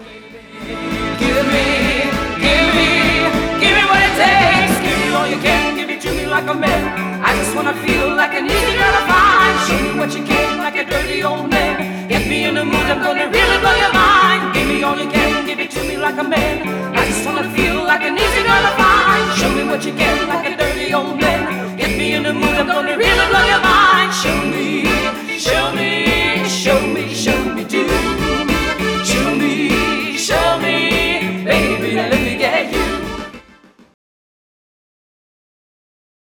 Enregistrement, Studio
Guitares: Électrique / Acoustique
Piano / Orgue
Choriste